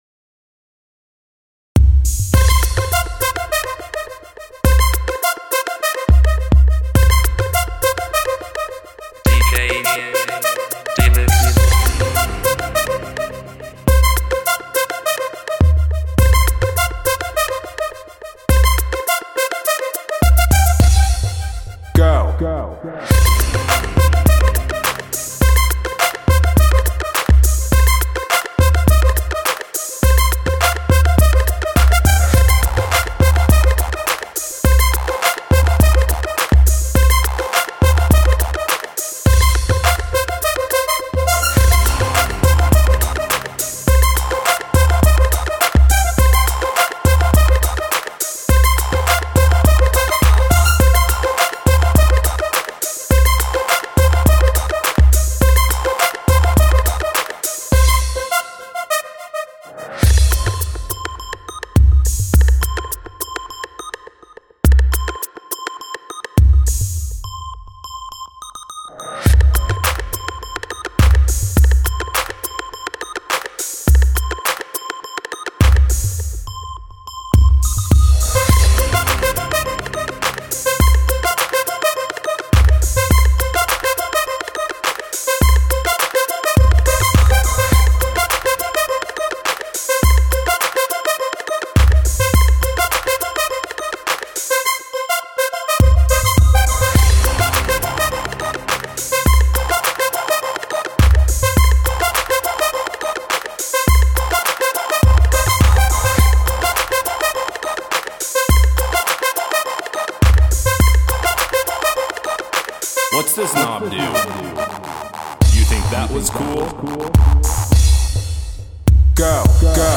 Жанр:Electro house